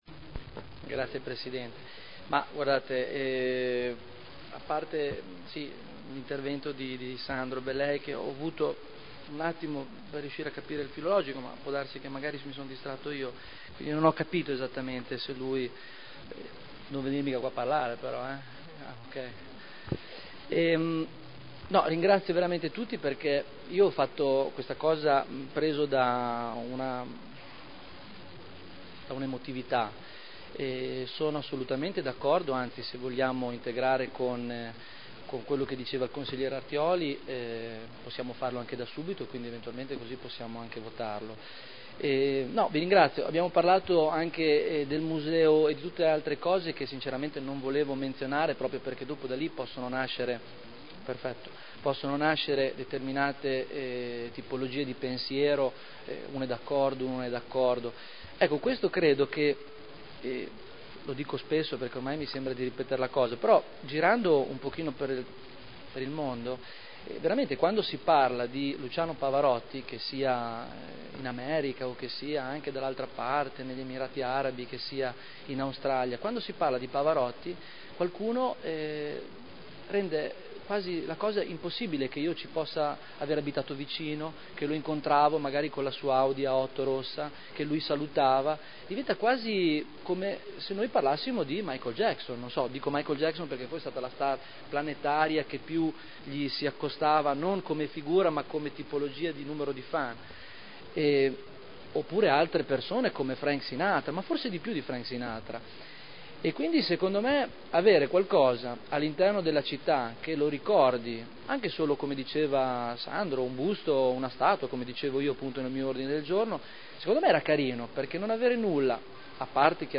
Seduta del 11/11/2010. Conclude dibattito su Ordine del Giorno presentato dal gruppo consiliare Lega Nord avente per oggetto: “Luciano Pavarotti”